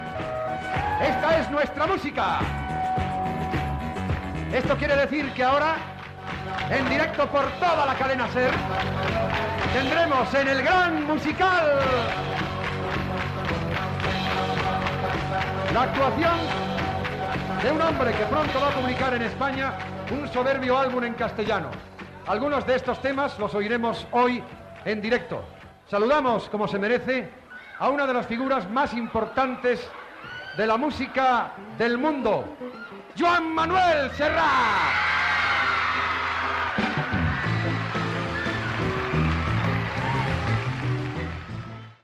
Identificació del programa i presentació de Joan Manuel Serrat
Musical